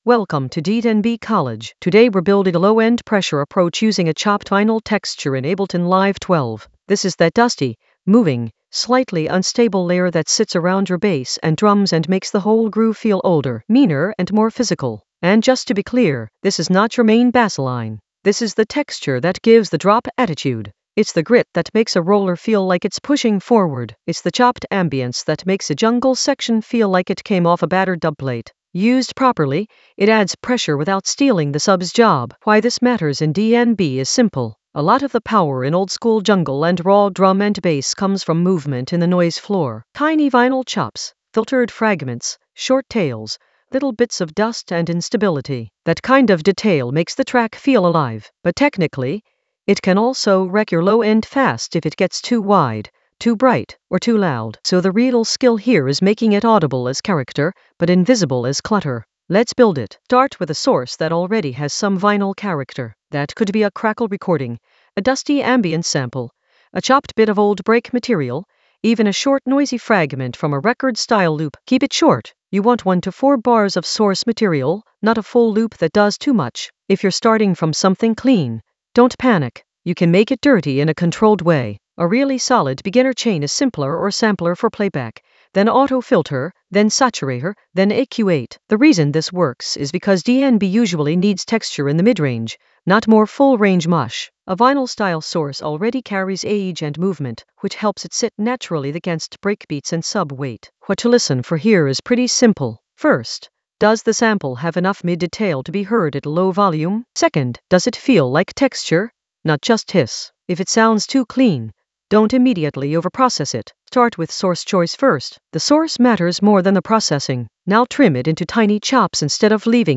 Narrated lesson audio
The voice track includes the tutorial plus extra teacher commentary.
An AI-generated beginner Ableton lesson focused on Low-End Pressure approach: a chopped-vinyl texture drive in Ableton Live 12 for jungle oldskool DnB vibes in the FX area of drum and bass production.